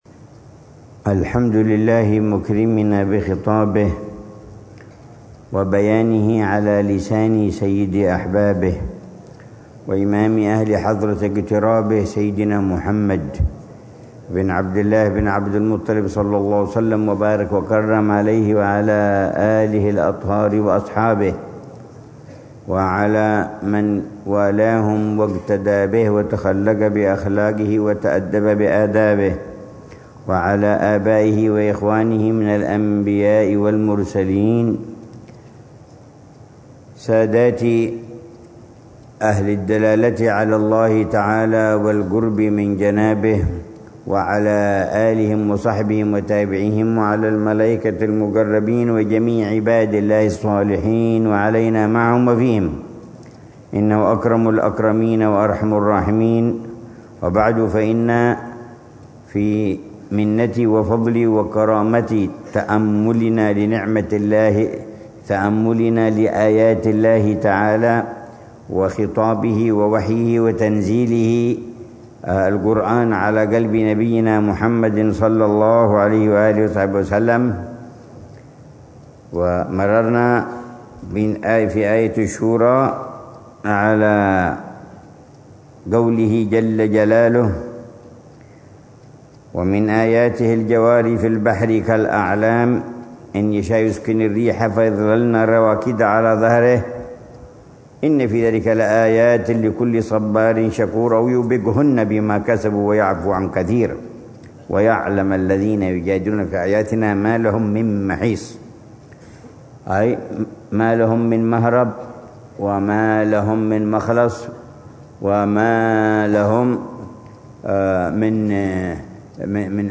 الدرس الثالث عشر
ضمن الدروس الصباحية لشهر رمضان المبارك من عام 144